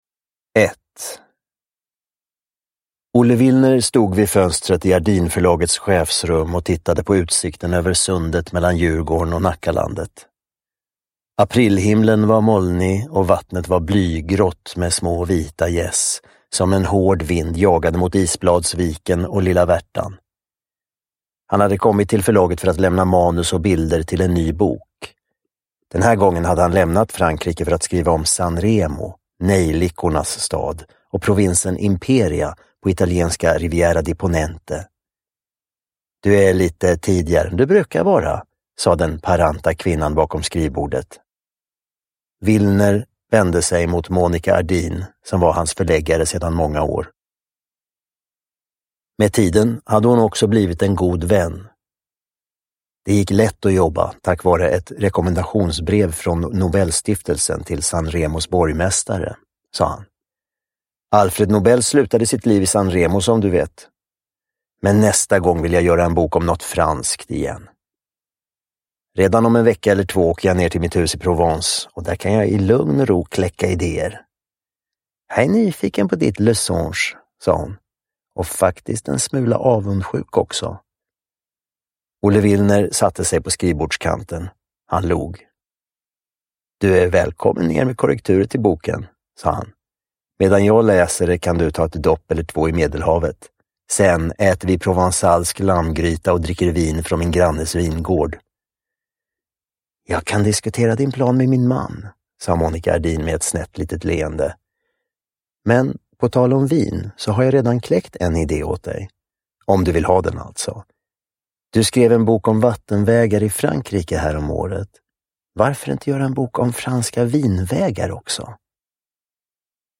Uppläsare: Björn Kjellman